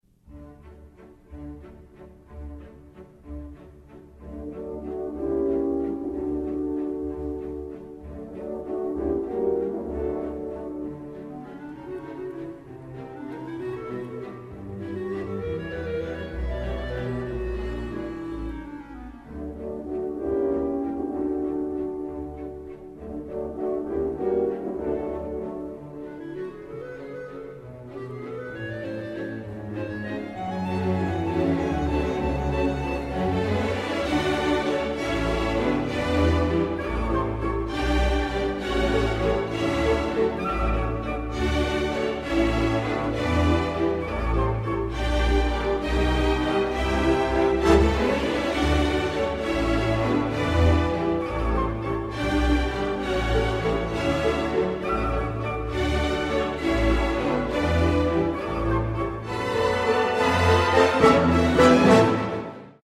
Вальс цветов из балета «Щелкунчик» П.И. Чайковского